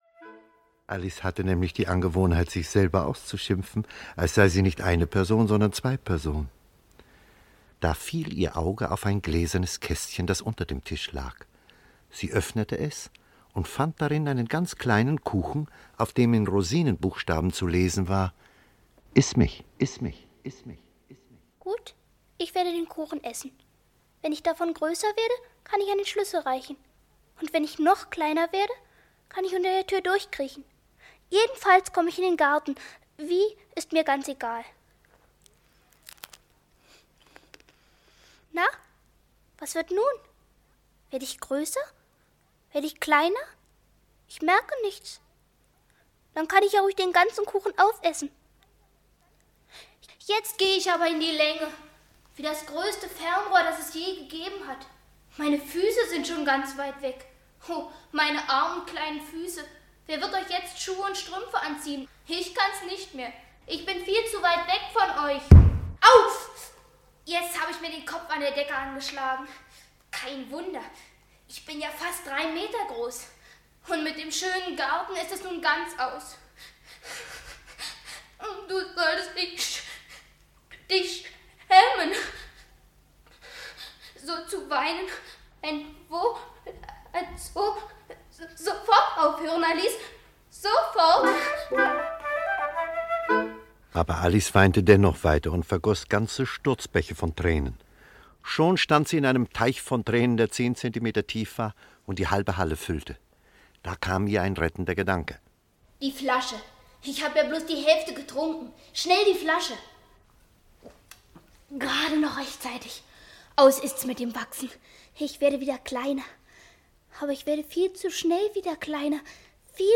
Alice im Wunderland - Lewis Carroll - Hörbuch